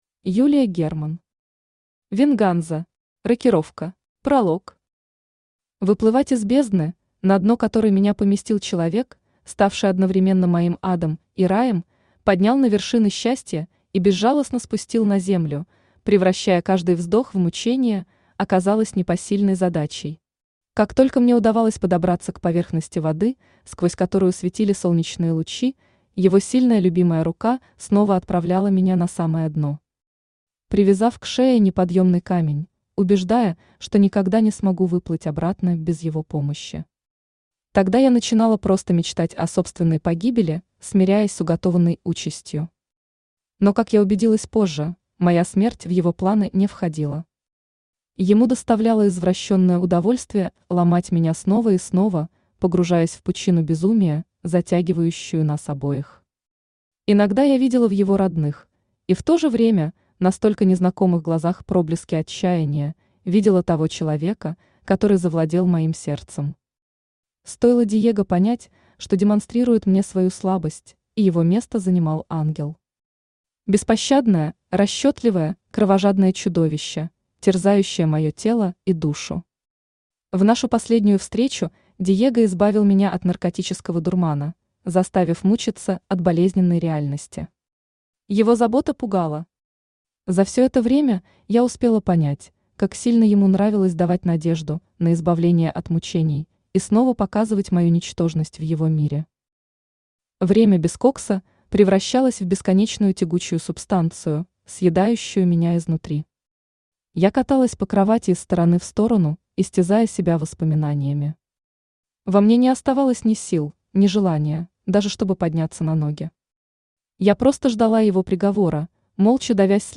Аудиокнига Венганза. Рокировка | Библиотека аудиокниг
Aудиокнига Венганза. Рокировка Автор Юлия Михайловна Герман Читает аудиокнигу Авточтец ЛитРес.